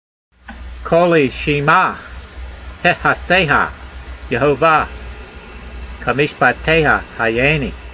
Sound (Psalm 119:149) Transliteration: ko l ee sheem' ah he 'has d ey ha, ye ho vah ke 'meeshpa t ey ha ha yay nee Vocabulary Guide: Hear my voice according to your loving kindness, Jehovah; save me according to your judgment s . Translation: Hear my voice according to your loving kindness, Jehovah; save me according to your judgments.